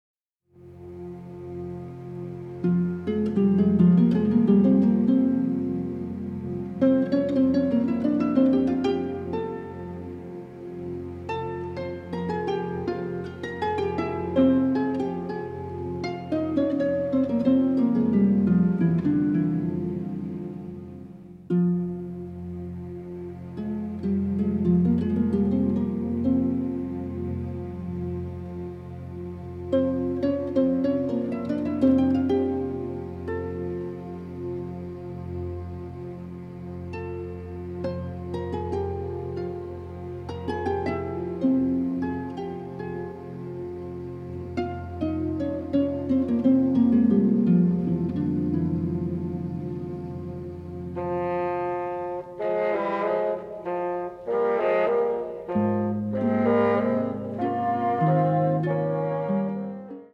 colorful orchestral tapestry is a true exotic delight.